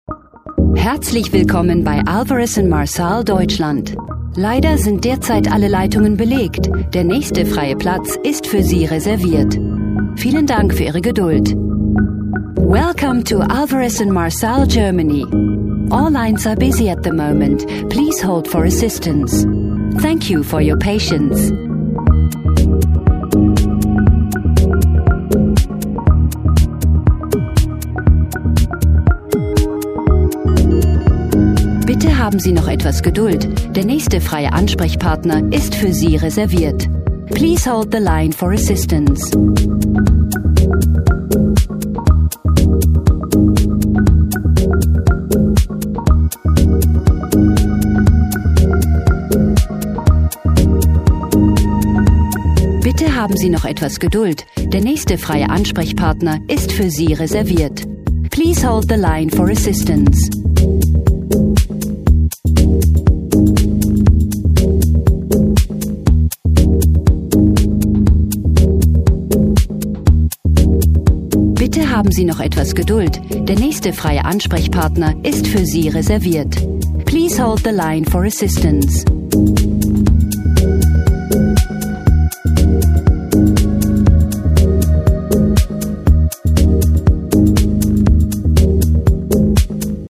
Guten Tag, Hello, Bonjour, Buenos Dias ! meine Stimme ist warm, verbindlich und freundlich, perfekt für Ihr Voice Over, Industriefilm/Doku.
deutsch - englische (uk) Sprecherin.
Sprechprobe: Werbung (Muttersprache):